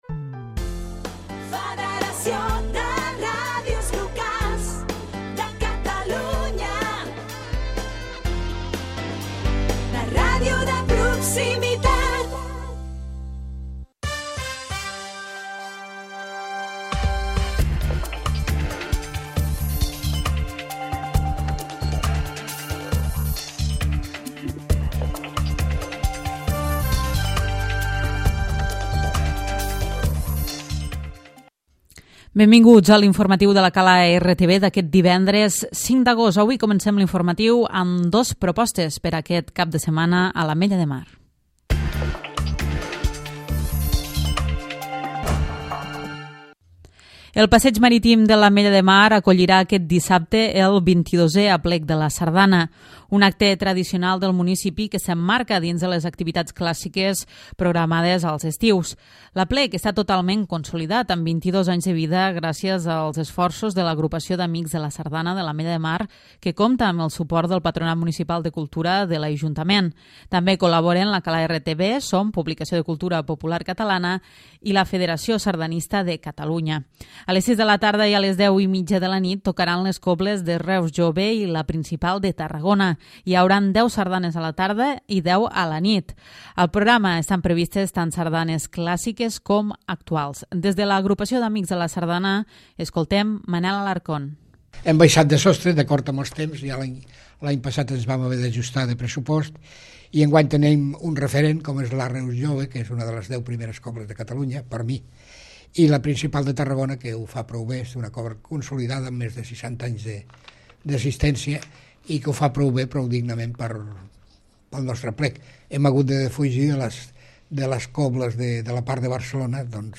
Butlletí infromatiu